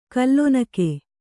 ♪ kallonake